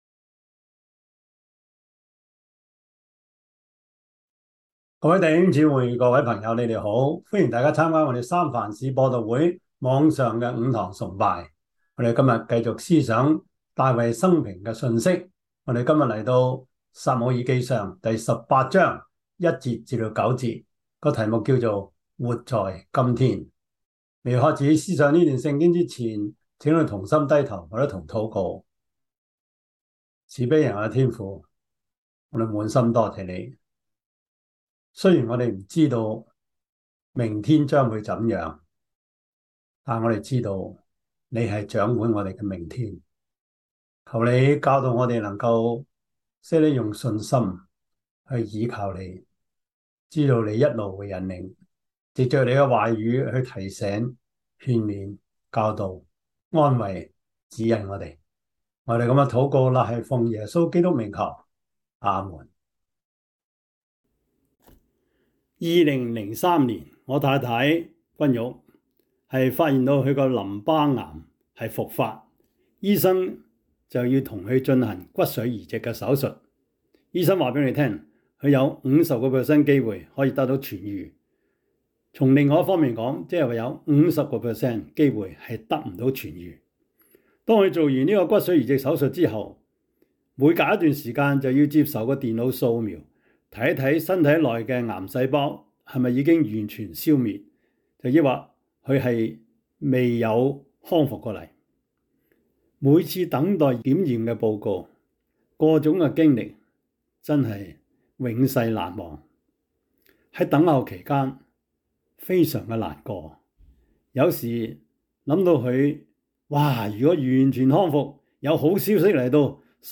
撒母耳記上 18:1-9 Service Type: 主日崇拜 撒 母 耳 記 上 18:1-9 Chinese Union Version